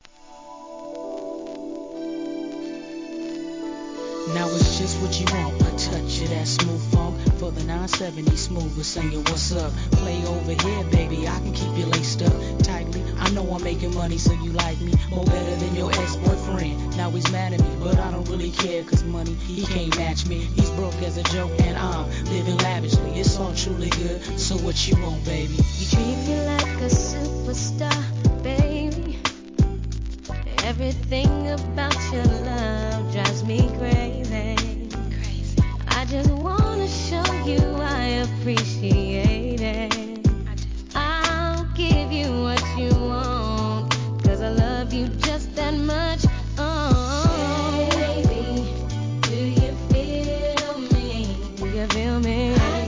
HIP HOP/R&B
メロ〜&キュート!